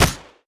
poly_explosion_bullet.wav